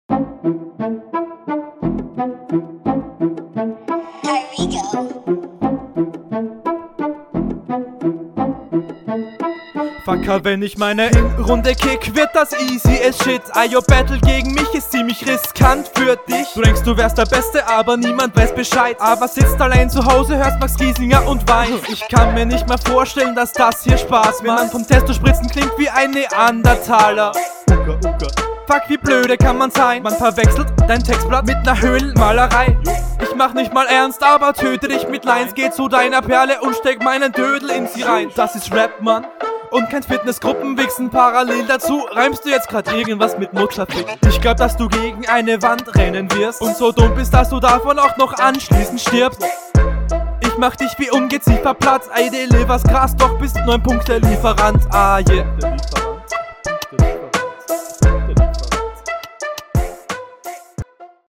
Abmische viel zu schwach.